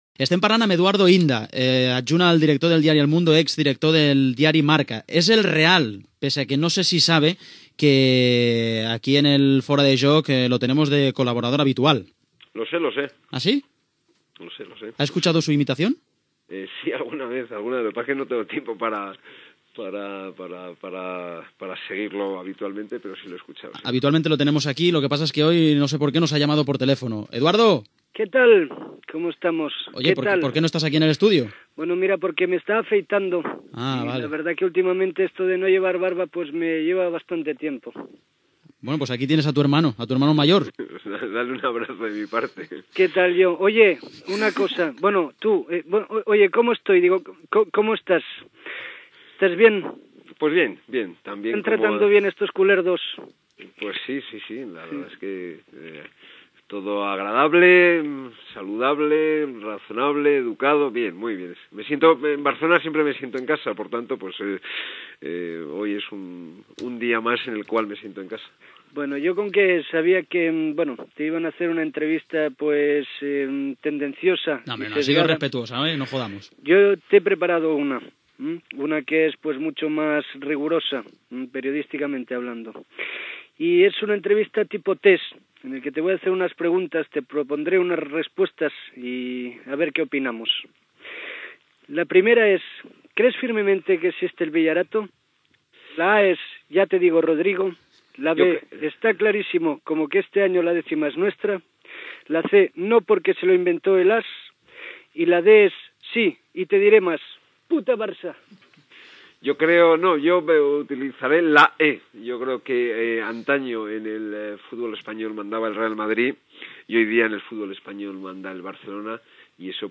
Espai "El test manipulat d'Eduardo Inda"fet al periodista Eduardo Inda, indicatiu del programa
Entreteniment